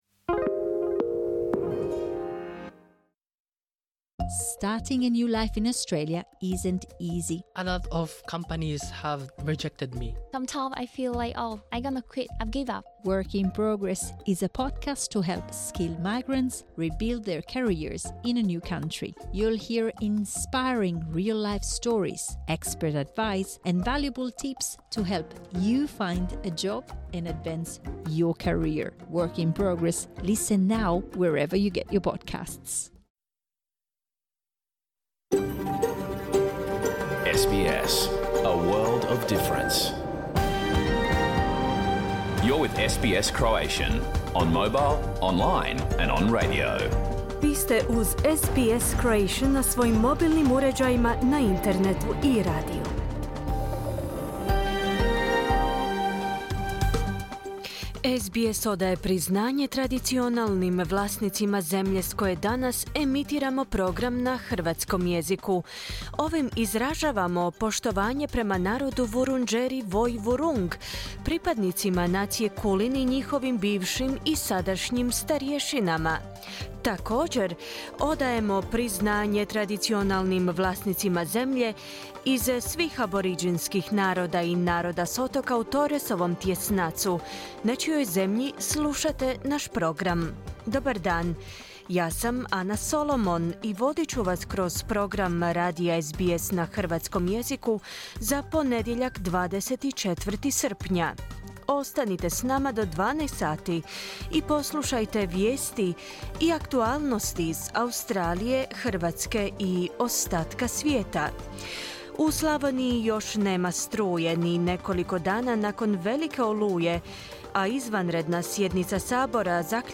Podcast programa koji je emitiran uživo u ponedjeljak, 24.7.2023. u 11 sati. Pregled vijesti i aktualnih tema iz Australije, Hrvatske i ostatka svijeta.